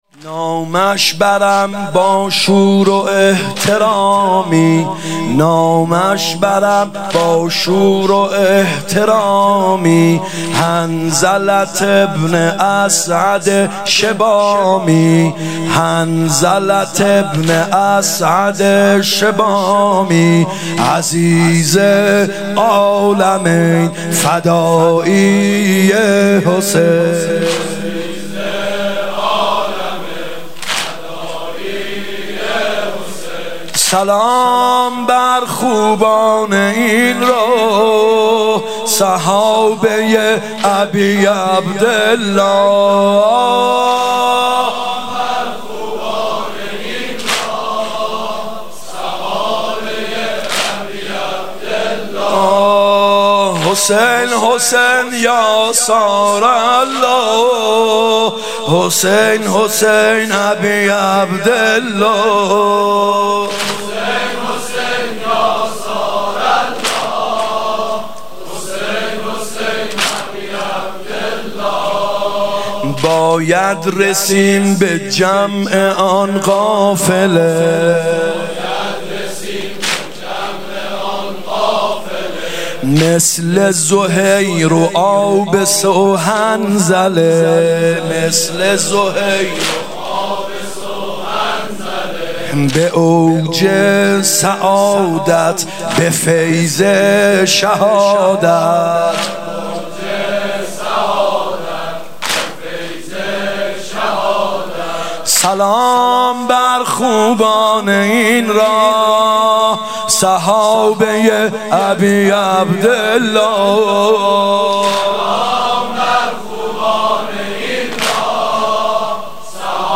هیات یامهدی عج(محرم 96)